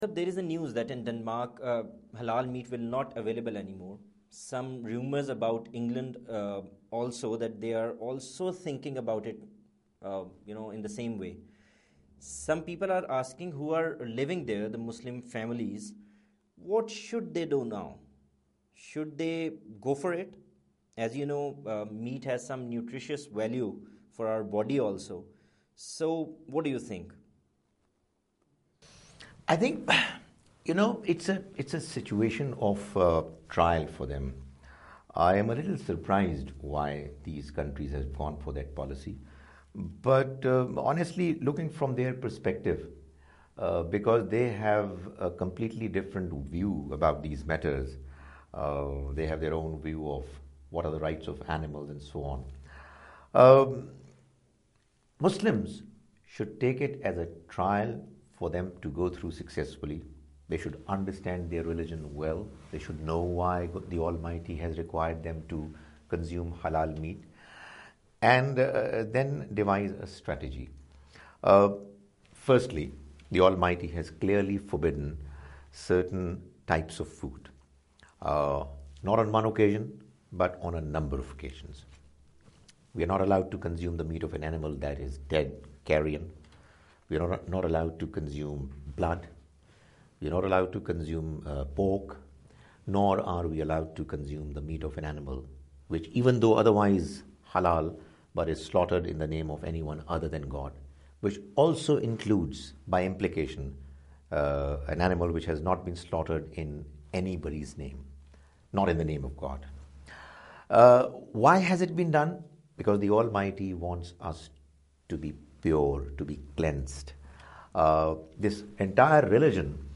Program "Ask A Question" where people ask questions and different scholars answer their questions